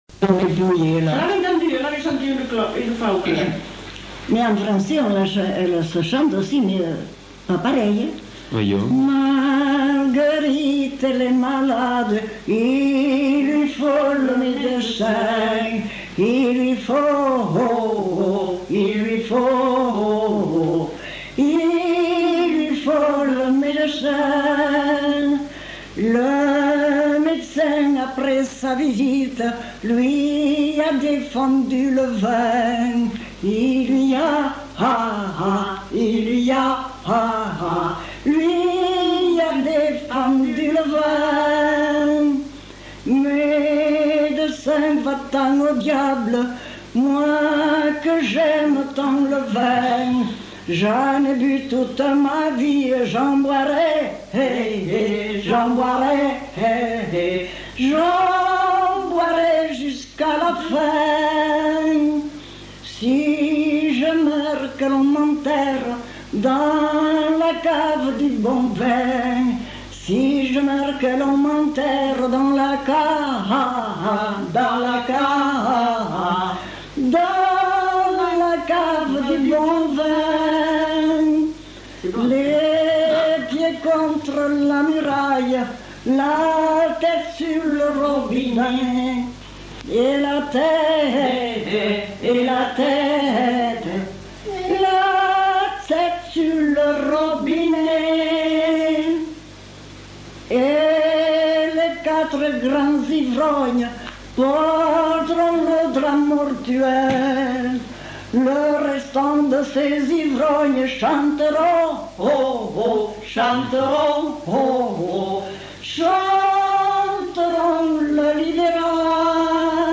Lieu : Mont-de-Marsan
Genre : chant
Effectif : 1
Type de voix : voix de femme
Production du son : chanté
Notes consultables : Les premiers mots ne sont pas enregistrés.